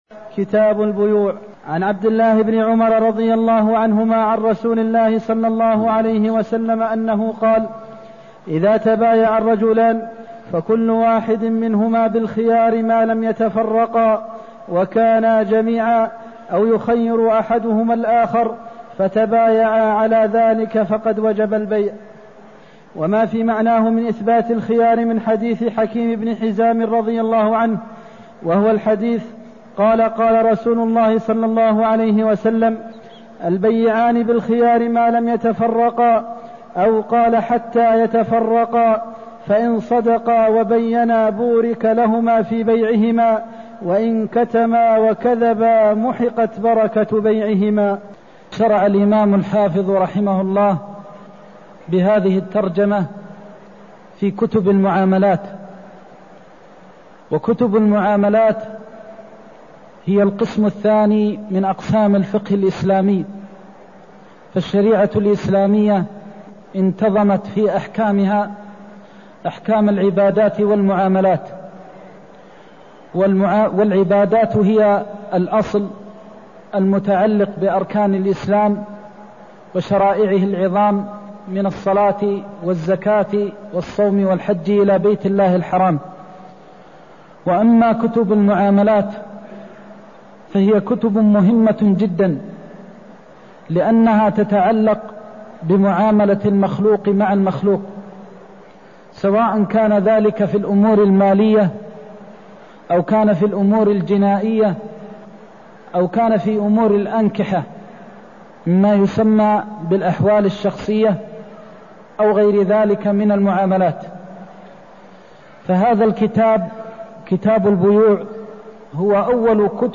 المكان: المسجد النبوي الشيخ: فضيلة الشيخ د. محمد بن محمد المختار فضيلة الشيخ د. محمد بن محمد المختار البيعان بالخيار (243)